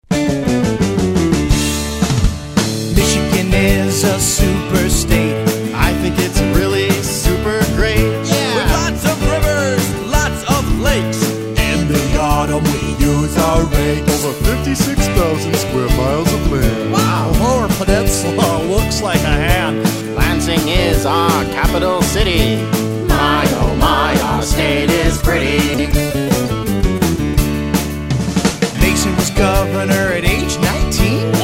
fun and quirky songs